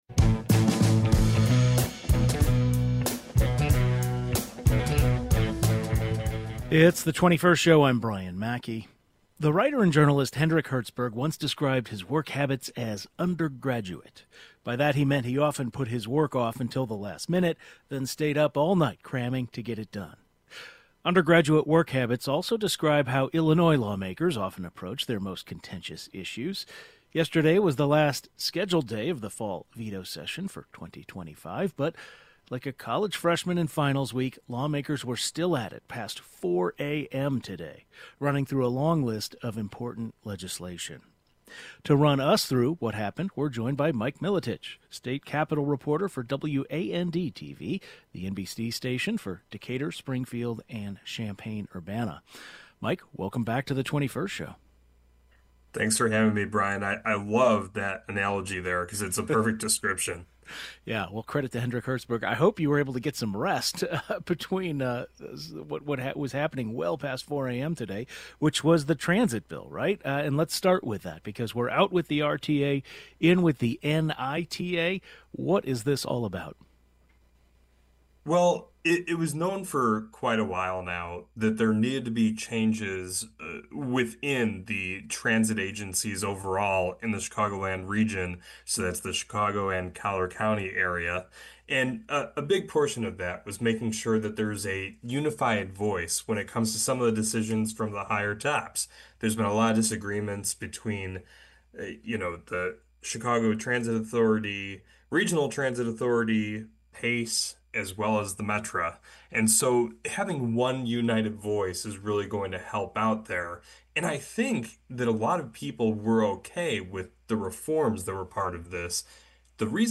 The Illinois General Assembly wrapped up a brief but highly productive fall legislative session in which they tackled the financial problems of mass transit agencies, energy prices, immigration enforcement, and "medical aid in dying" for terminally ill patients. We’ll talk with a reporter who was covering it well into the early hours of this morning.